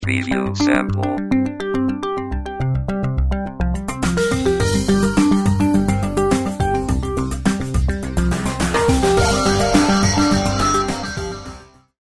Style: Instrumental Synth Pop Rock Music Ringtone
Quality: 192 kBit/s Stereo (Preview Samples: 64 kBit/s Mono)